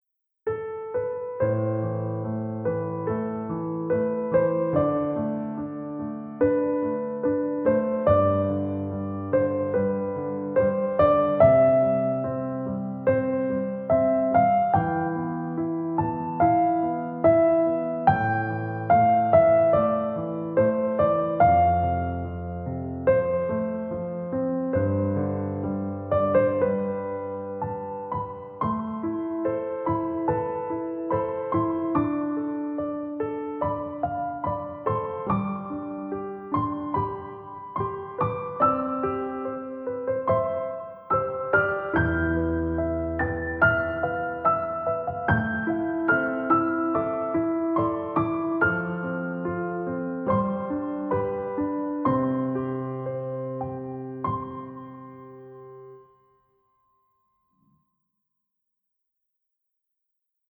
儚くて切ないピアノ曲です。